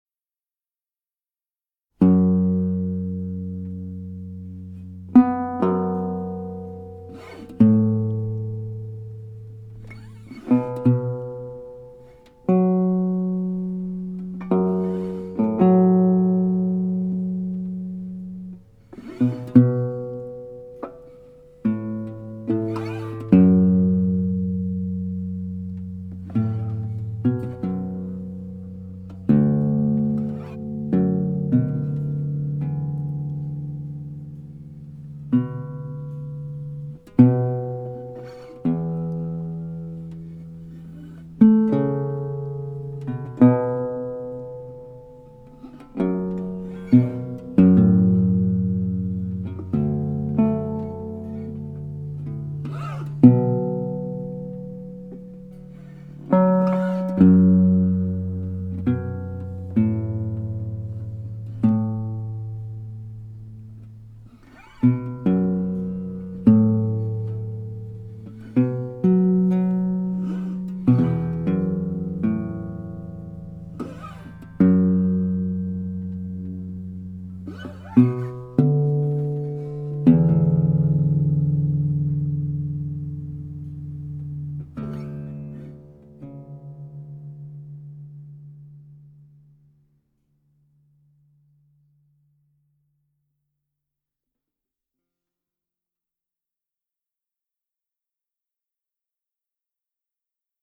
instrumental pieces